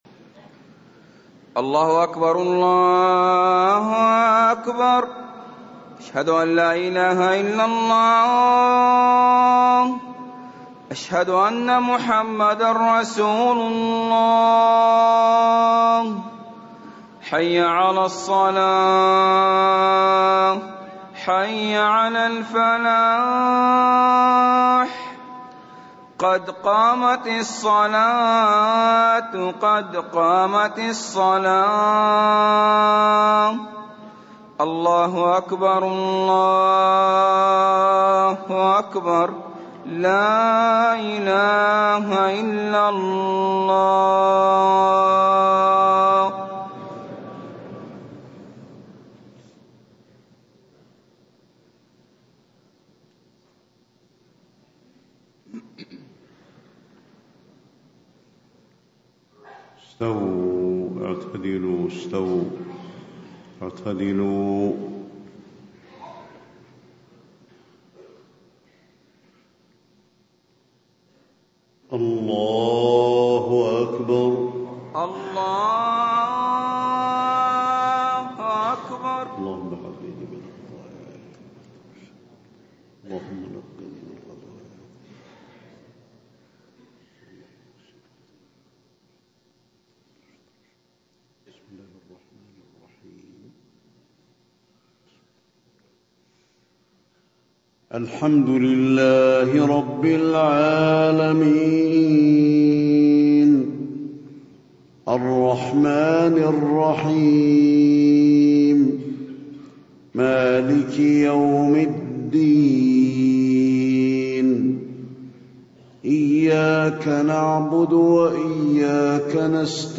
صلاة الفجر 1 ربيع الأول 1437هـ فواتح سورة الفرقان 1-31 > 1437 🕌 > الفروض - تلاوات الحرمين